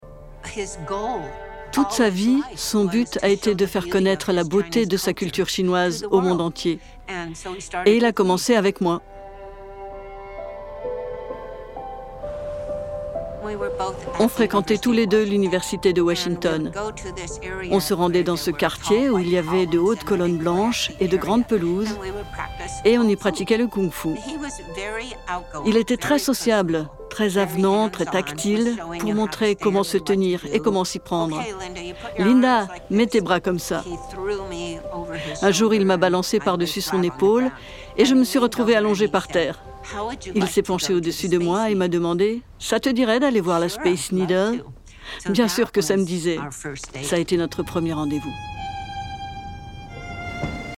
Voice over extrait